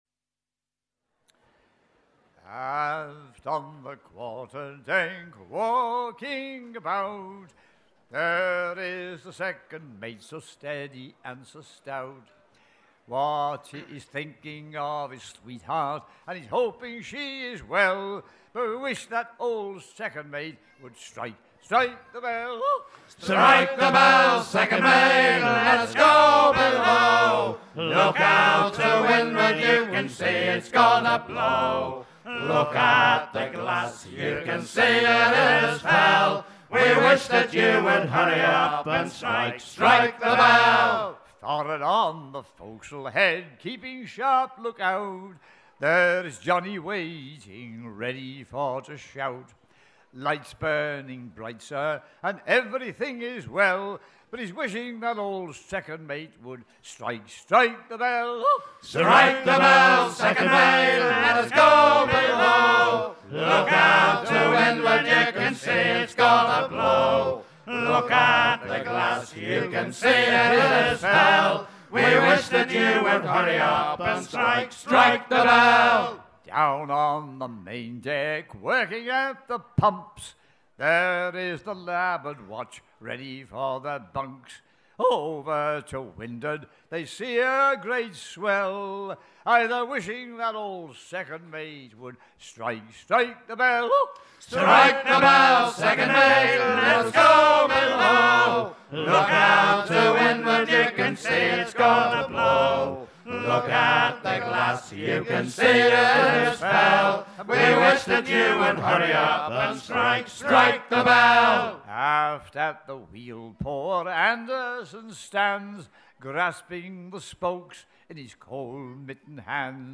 gestuel : à pomper
Genre laisse